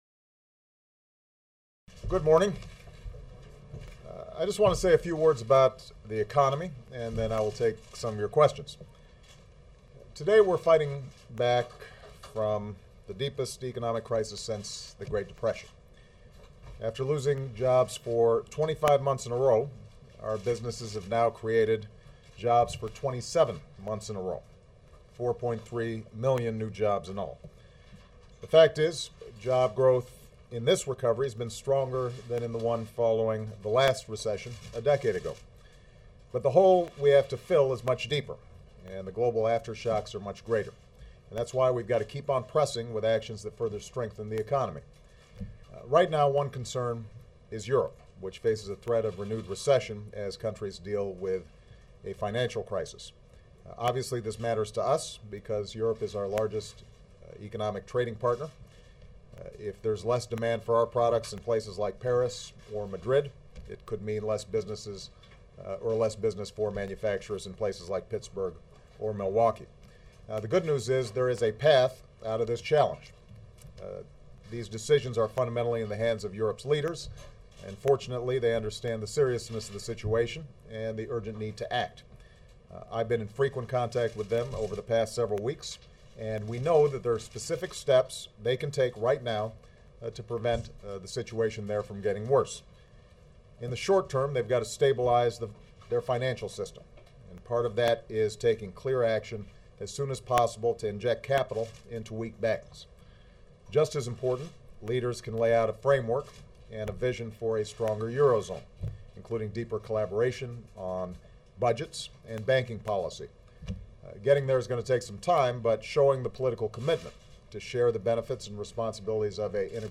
U.S. President Barack Obama holds a press conference on the economy